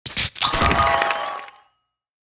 Sports, Athletics, Game Menu, Ui Unlock Item Sound Effect Download | Gfx Sounds
Sports-athletics-game-menu-ui-unlock-item.mp3